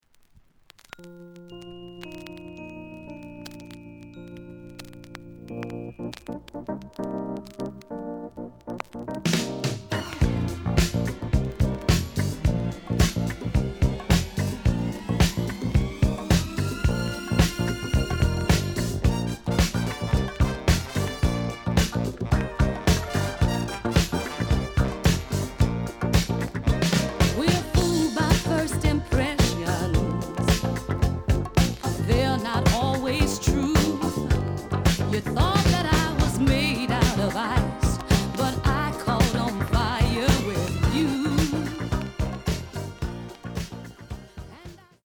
The listen sample is recorded from the actual item.
●Genre: Soul, 70's Soul
Some noise on beginnig of A side.